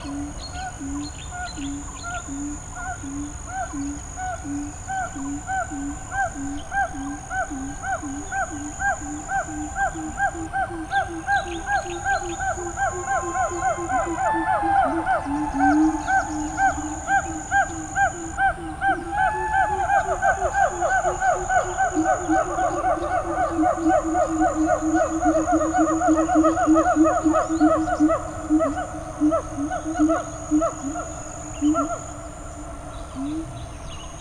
수마트라섬에서 녹음된 큰긴팔원숭이 소리 (2022년)